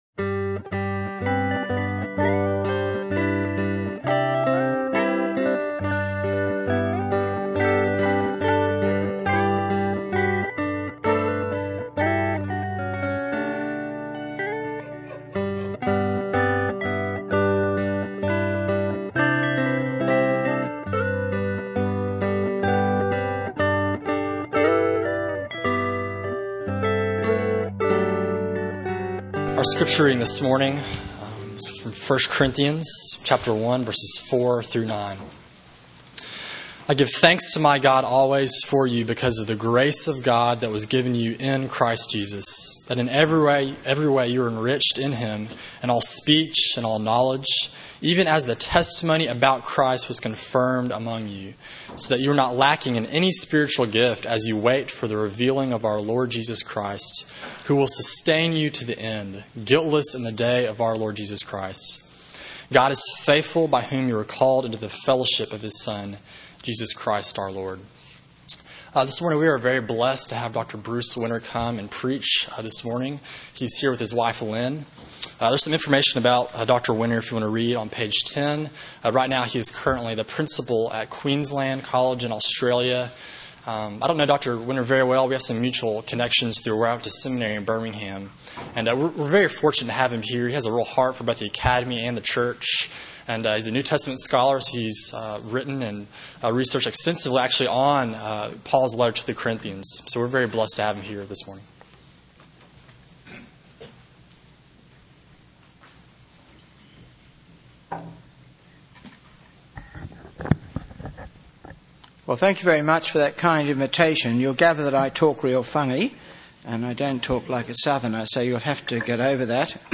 Sermon on 1 Corinthians 1:4-9 from January 11